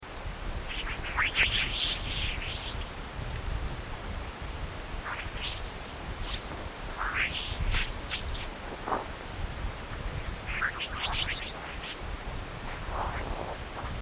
A wind of fast moving particles blows out from our Sun, and although space transmits sound poorly, particle impact and variable-field data from NASA's near-Sun Parker Solar Probe is being translated into sound.
psp_dispersive_chirping_waves_2.mp3